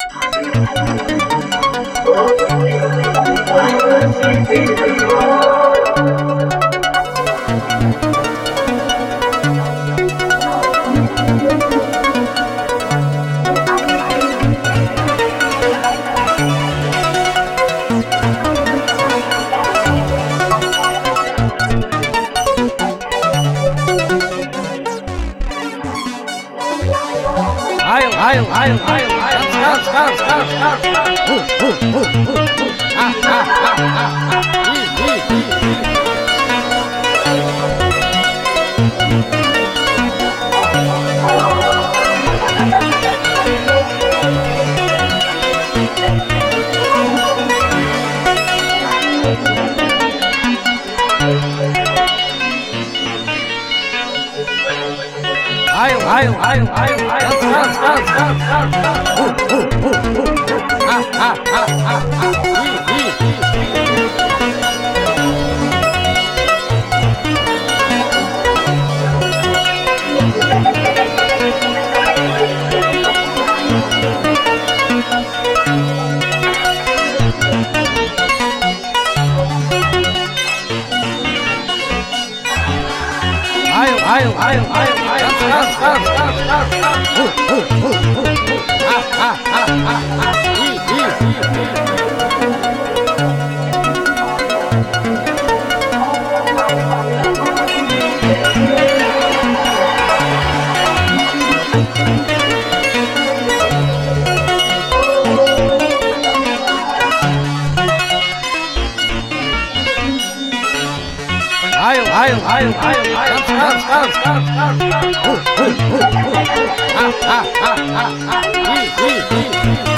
Genre: EBM, IDM.